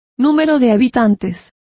Complete with pronunciation of the translation of populations.